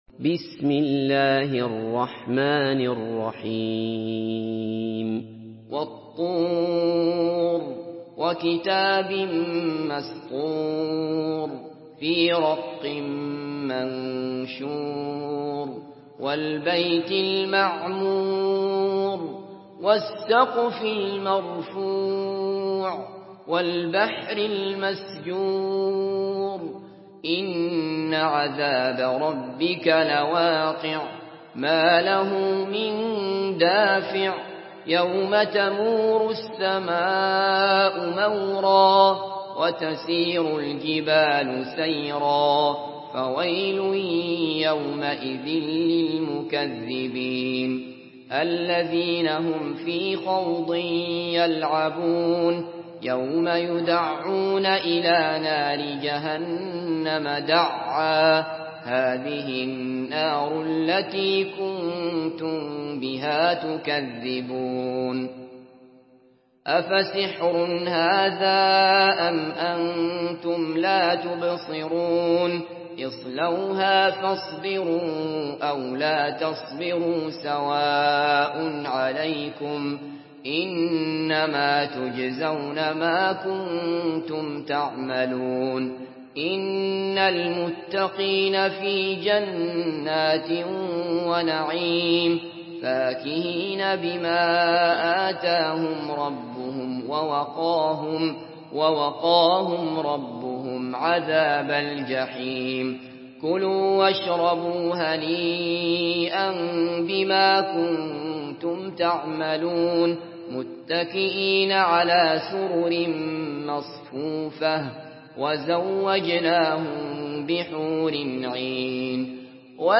Surah Tur MP3 by Abdullah Basfar in Hafs An Asim narration.
Murattal Hafs An Asim